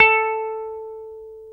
Index of /90_sSampleCDs/Roland LCDP02 Guitar and Bass/GTR_Dan Electro/GTR_Dan-O Guitar